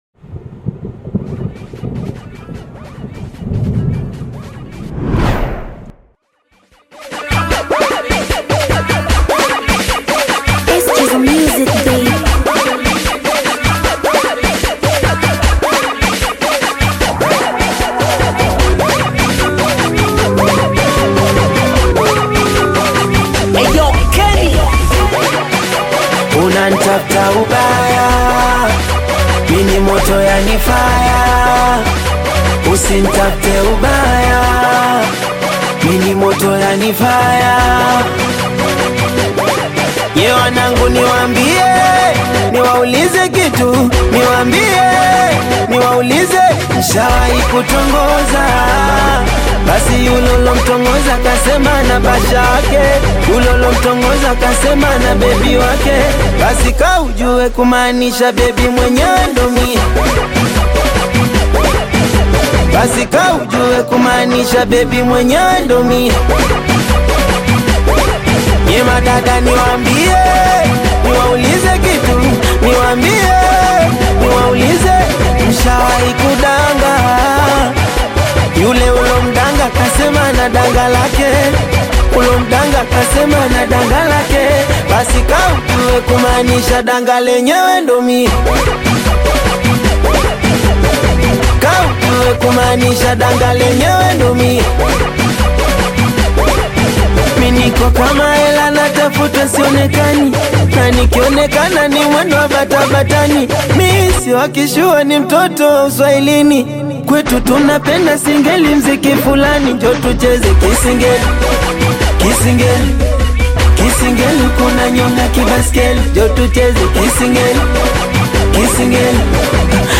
Bongo Flava
This is another singeli song that will sure make you dance.
Singeli